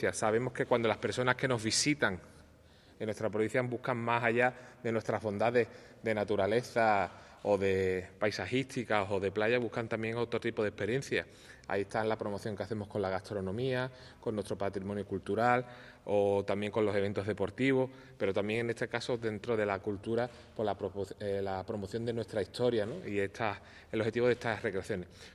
Unos 60.000 euros en conjunto destinados a facilitar su organización y, por tanto, potenciar su promoción, ha explicado el responsable del Área de Desarrollo de la Ciudadanía de Diputación, Jaime Armario , durante la presentación del cartel de estas nueve recreaciones históricas en Algodonales, El Bosque, Tarifa, Ubrique, Algar, Benamahoma, Grazalema, Setenil y Zahara de la Sierra.